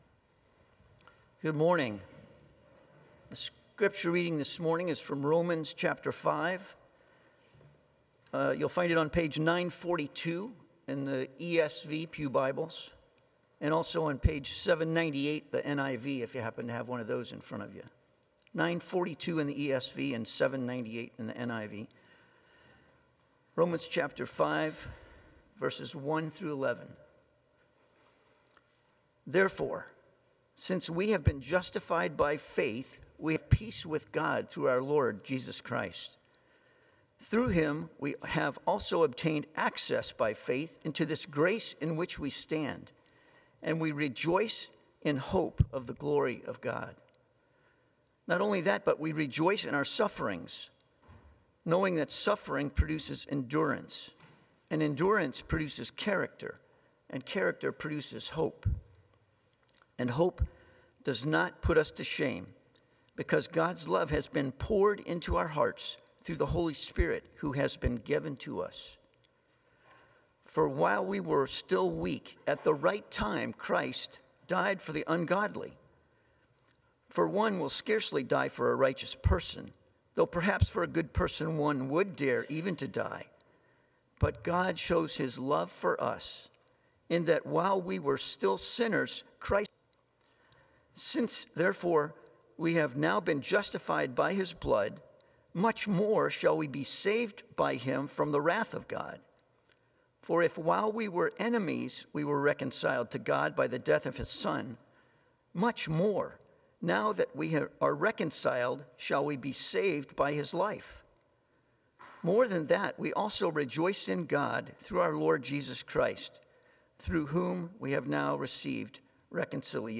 Passage: Romans 5:2 Sermon